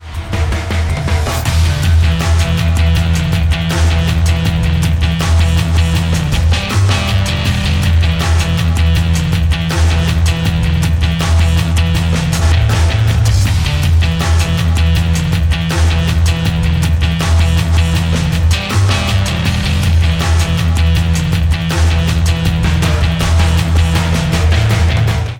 • Качество: 128, Stereo
гитара
саундтреки
без слов
инструментальные